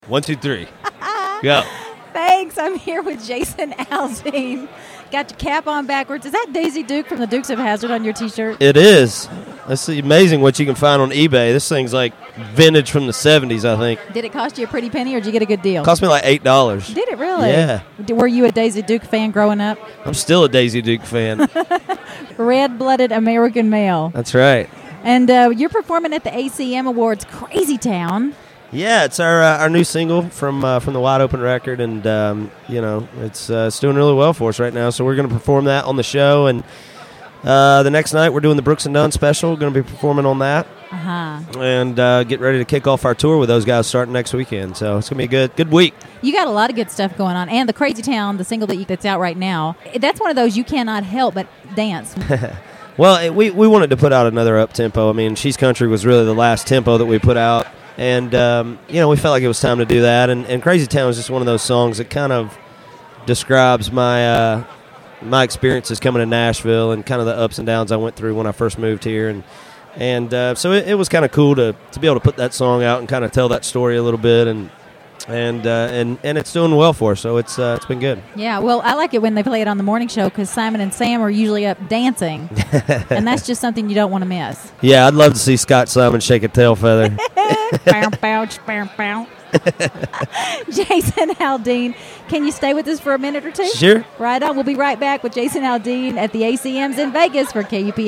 Jason Aldean Interview, Part 1, 2010 ACM Awards